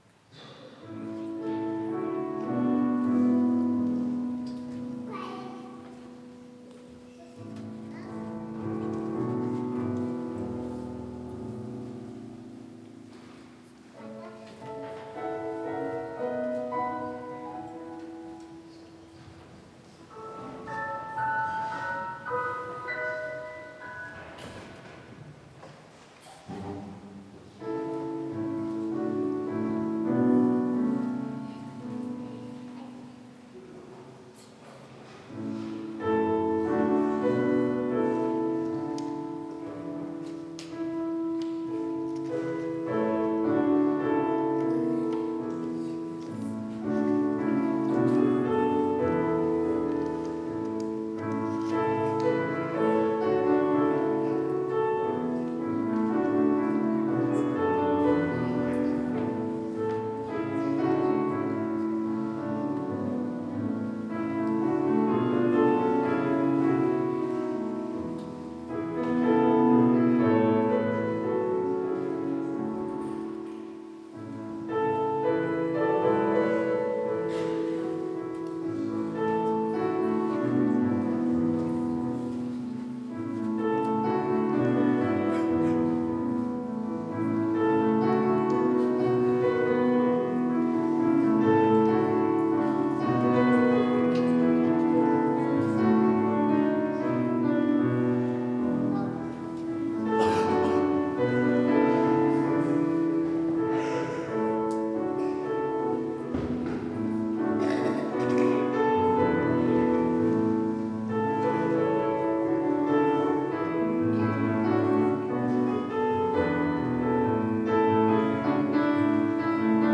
Orgelstück zum Ausgang
Audiomitschnitt unseres Gottesdienstes vom 2. Sonntag nach Ostern 2025.